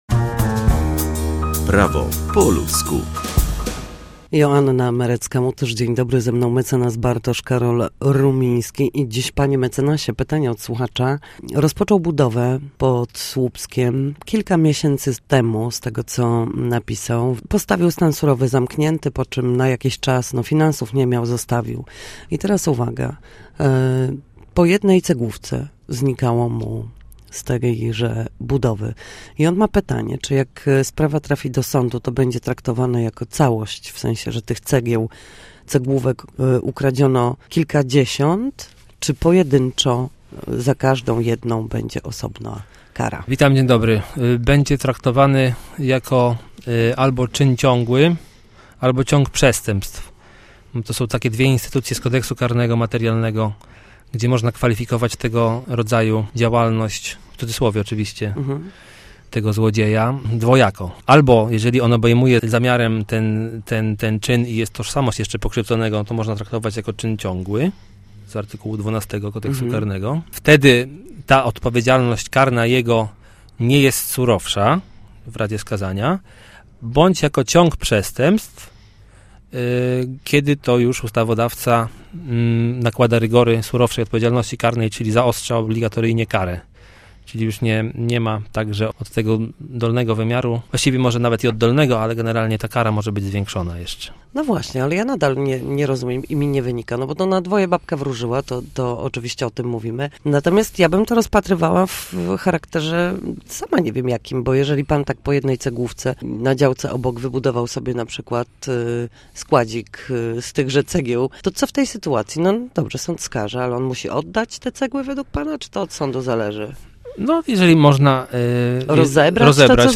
W każdy piątek o godzinie 7:20 i 13:40 na antenie Studia Słupsk przybliżamy Państwu meandry prawa. Nasi goście, prawnicy, odpowiadać będą na jedno pytanie dotyczące zachowania w sądzie i podstawowych zagadnień prawniczych.